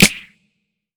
Unit ClapSnare.wav